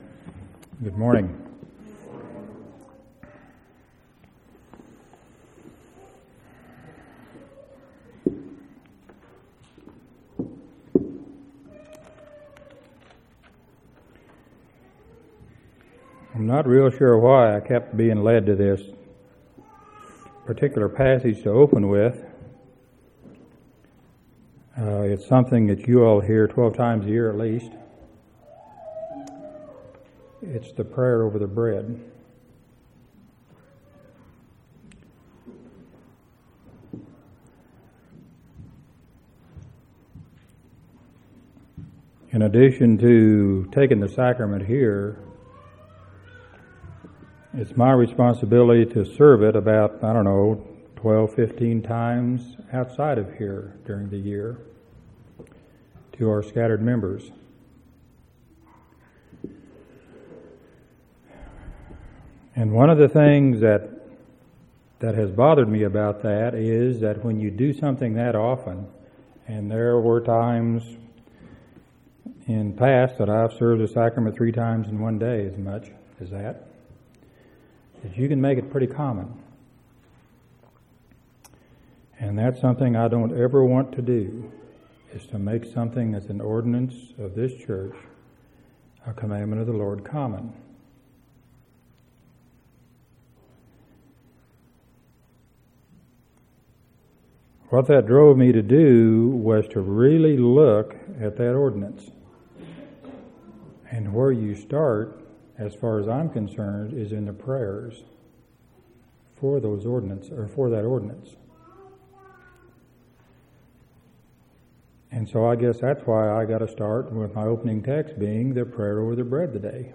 12/18/2005 Location: Temple Lot Local Event